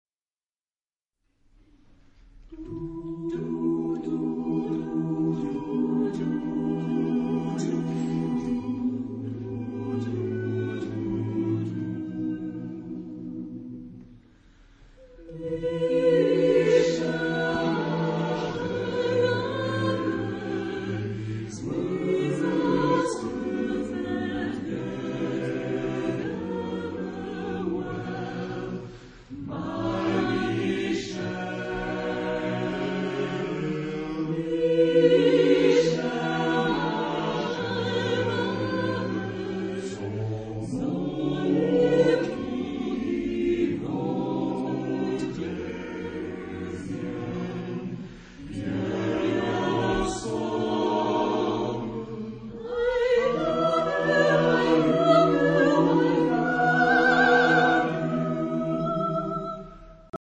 Caractère de la pièce : librement ; lié ; binaire ; calme
Type de choeur : SATB (div)  (4 voix mixtes )
Tonalité : si bémol majeur ; fa mineur ; si bémol mineur
Réf. discographique : 7. Deutscher Chorwettbewerb 2006 Kiel